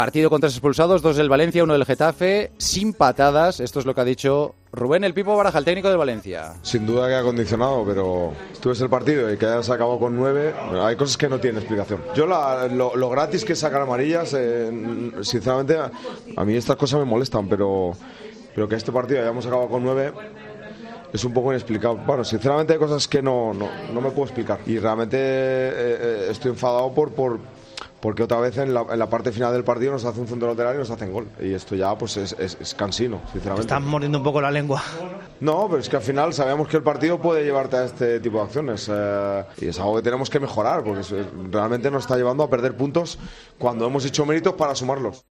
El entrenador del Valencia atendió a Movistar+ al término de la derrota ante el Getafe: "Estoy enfadado porque otra vez en la parte final del partido nos hacen un centro y gol"